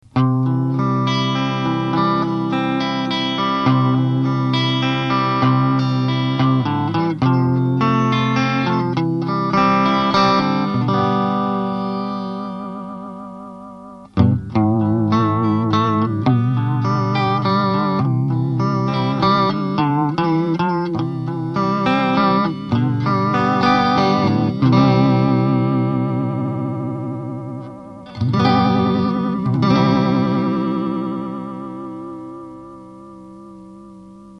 Hangminták
Vibrátó (mix off)
vibr_mixoff.mp3